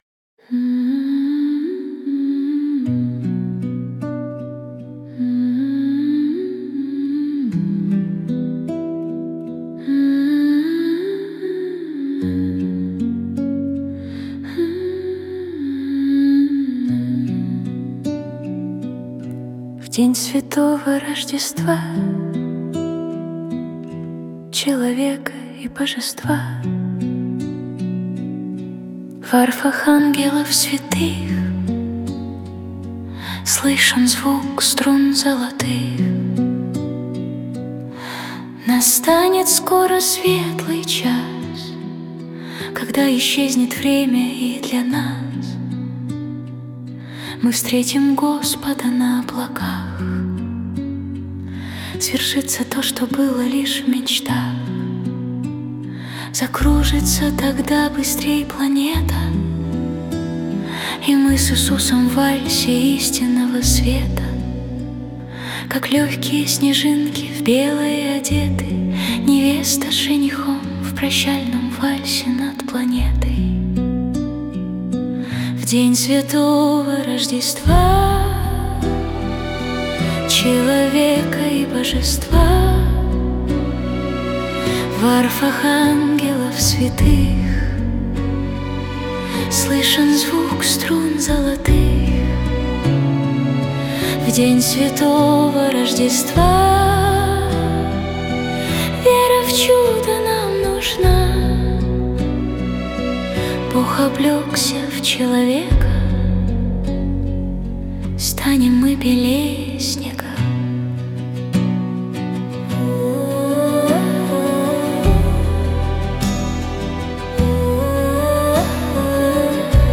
песня ai
179 просмотров 622 прослушивания 40 скачиваний BPM: 77